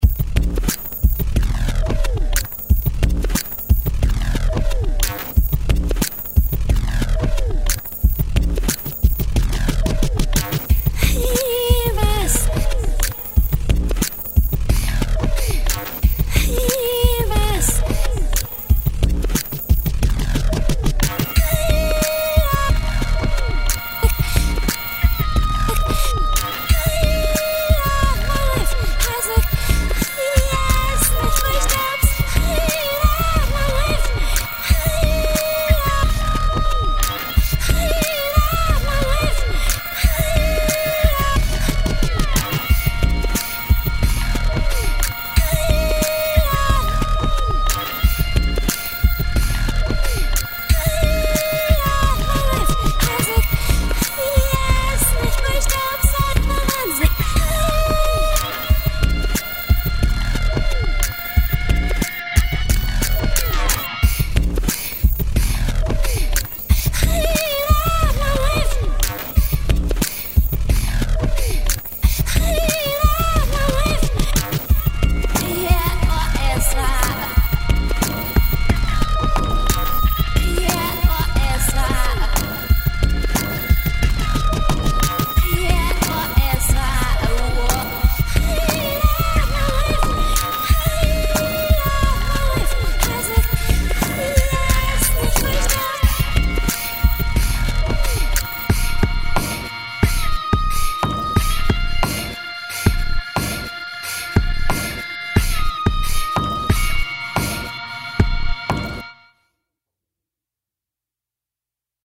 Im letzten Audiodemo kombiniere ich einige Rückwärts-Loops (Ausschnitte) aus der bereits verwendeten Gesangspassage mit einem Beat aus dem Construction Kit „Gravitation“. Hinzu kommt noch ein Loop aus der Library Ueberschall Feedback Guitar. Gitarre und Beat musste ich transponieren, um dieses Material der Tonart des Gesangs anzupassen. Die Gitarre habe ich mit einer Effekt/Amp-Kette aus Positiv Grid Bias FX Professional etwas schneidender gemacht. Sollte der Gesang streckenweise etwas angezerrt klingen, so liegt das an der bis zum Anschlag des VU-Meters übersteuerten Kombination aus Bandsättigung und Röhren-Vorstufe von Slate Digital mit dem Namen „Revival“.